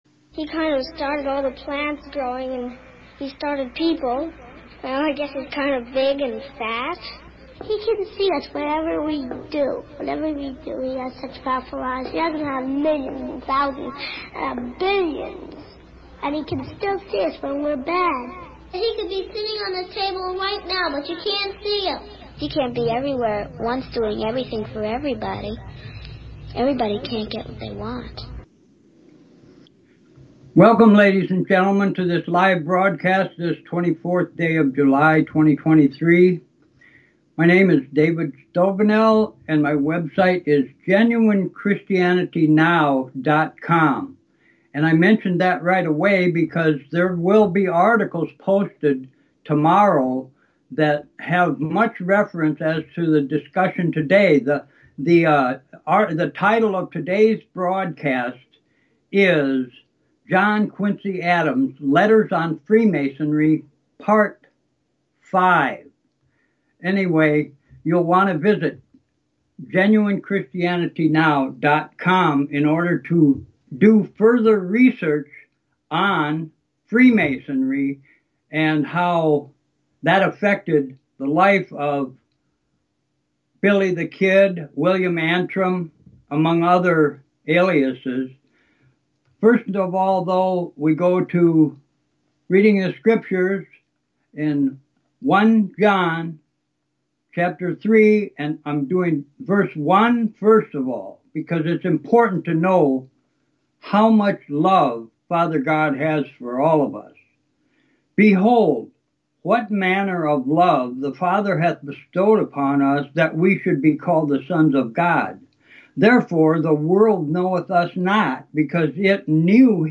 In Letters on Freemasonry by John Quincy Adams Part 5 the broadcast started with reading some verses from 1 John 3 (K.J.V.) about the love of the Father and how dreadful sin is. Then I asked everyone to consider going to the River Crest Publishing website and ordering the book from them because it is a very important record about what Freemasonry really is.